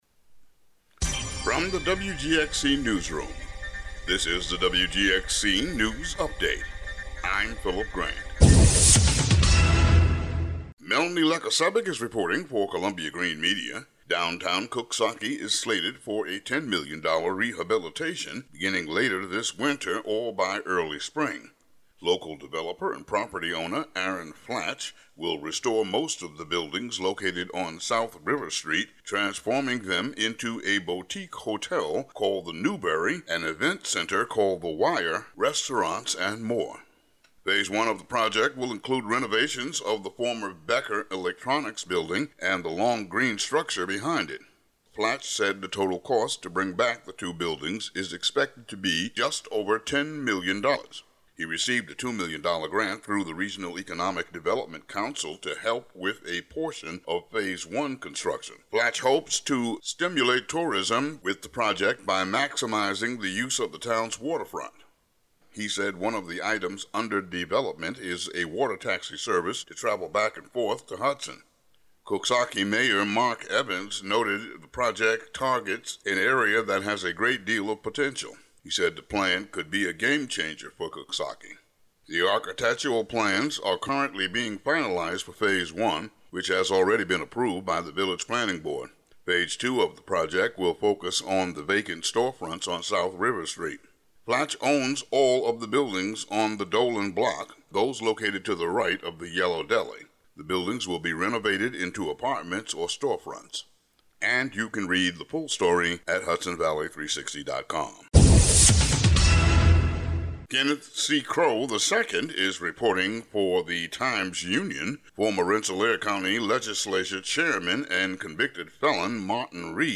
WGXC Local News Update (Audio)